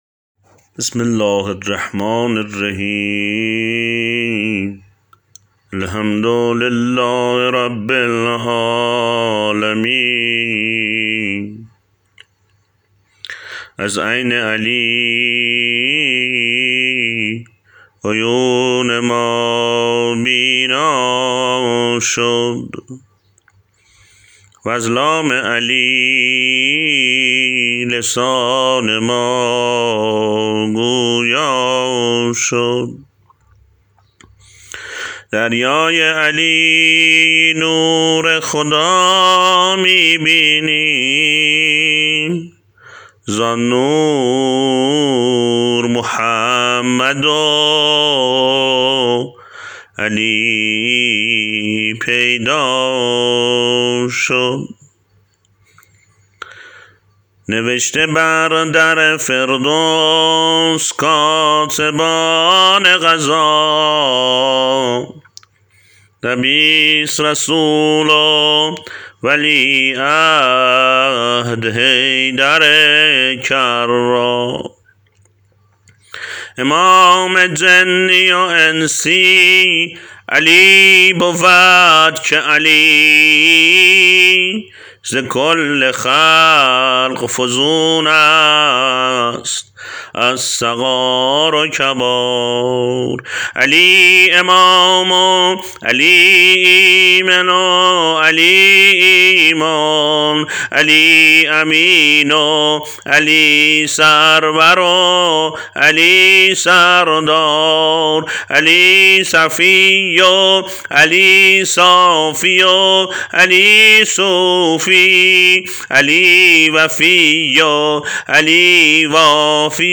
مداحان اهل و بیت , نوحه صوتی